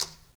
Conga-Tap1_v1_rr1_Sum.wav